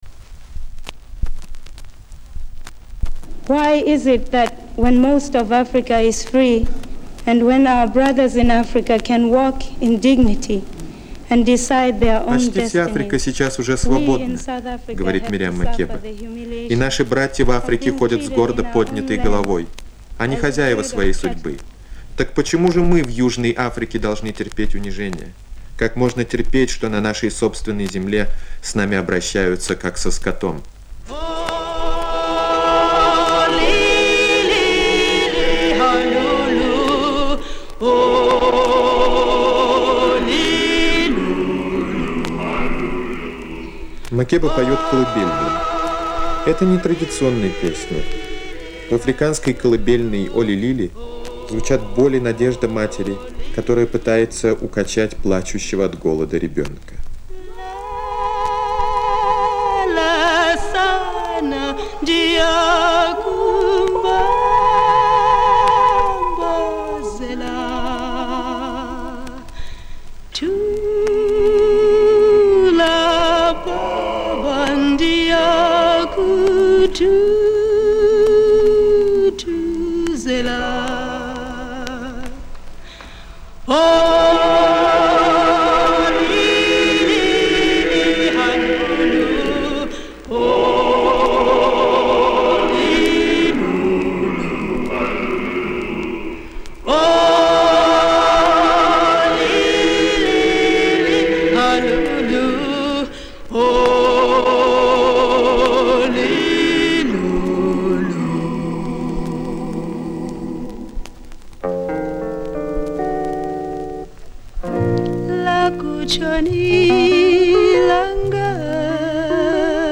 Одиннадцатая звуковая страница и начинается её речью.